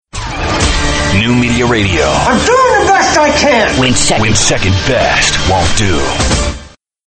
RADIO IMAGING